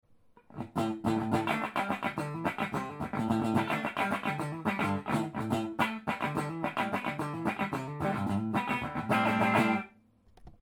このギターでカッティングしないという選択肢はありません。
まるでベースでチョッパーしているようなビックリする音がします。